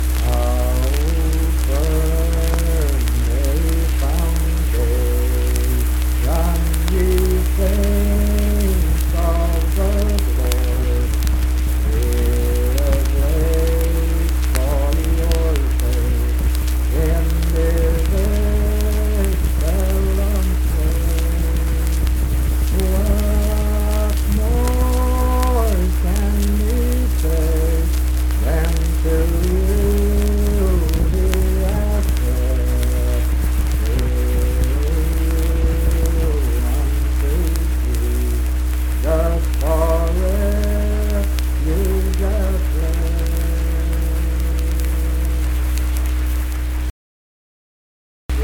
Unaccompanied vocal music
Hymns and Spiritual Music
Voice (sung)